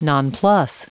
Prononciation du mot nonplus en anglais (fichier audio)
Prononciation du mot : nonplus